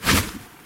近战战斗的声音 " Woosh 2
描述：冲床
标签： swosh swhish 冲床 近战手 woosh 打嗖
声道立体声